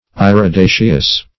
Iridaceous \Ir`i*da"ceous\, Irideous \I*rid"e*ous\, a. [From NL.